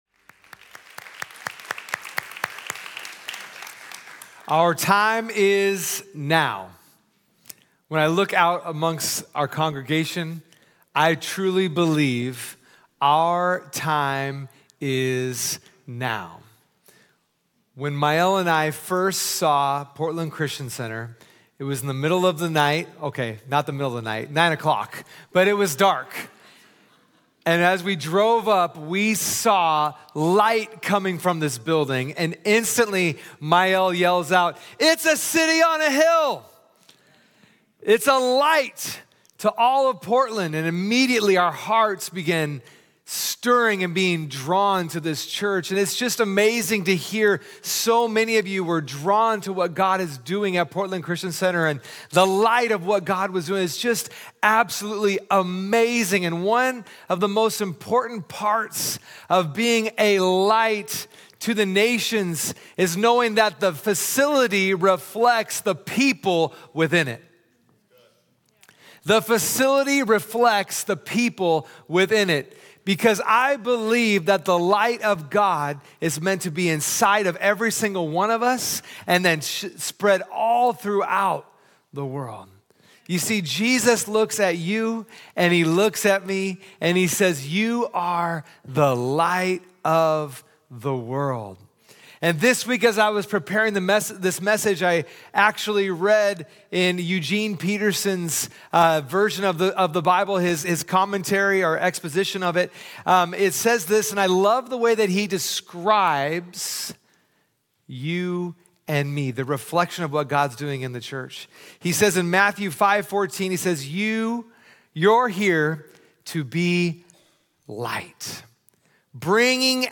Sunday Messages from Portland Christian Center The Treasure Principle, Part 1 Oct 13 2024 | 00:46:09 1x 00:00 / 00:46:09 Subscribe Share Spotify RSS Feed Share Link Embed